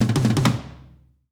British SKA REGGAE FILL - 06.wav